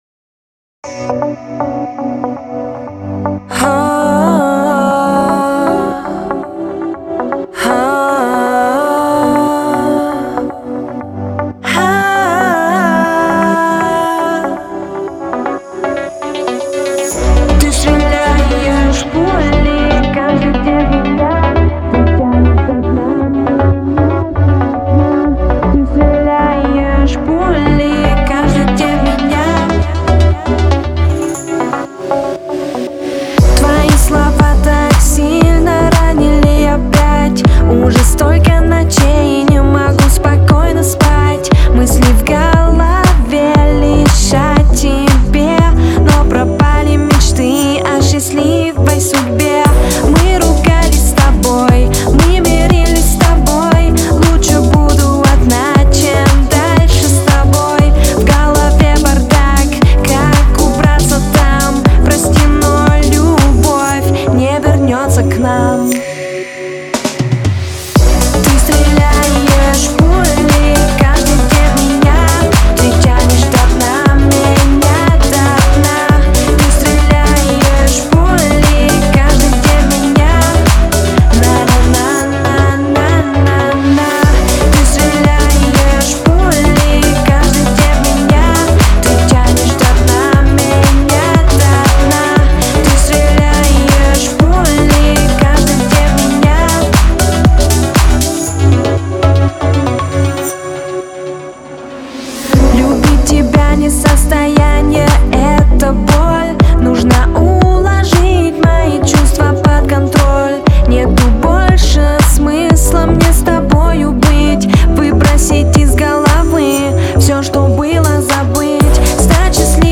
это мощная композиция в жанре русского рэпа